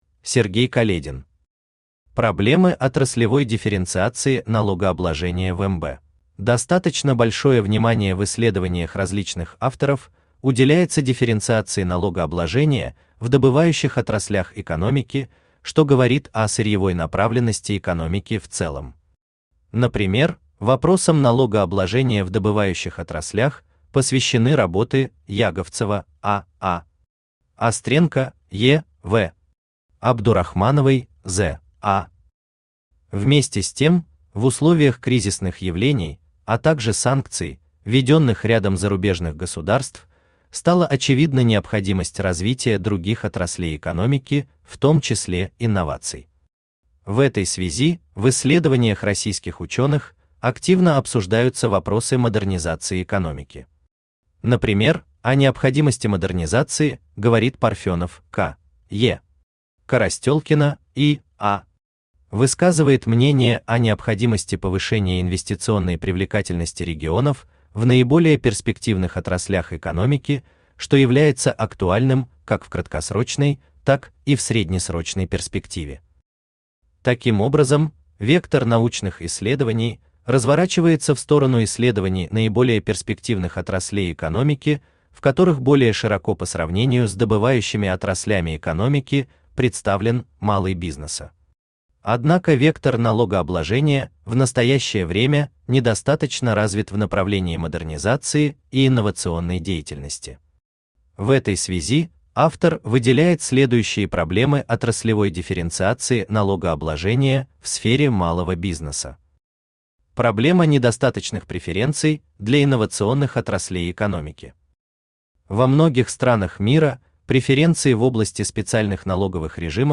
Аудиокнига Проблемы отраслевой дифференциации налогообложения в МБ | Библиотека аудиокниг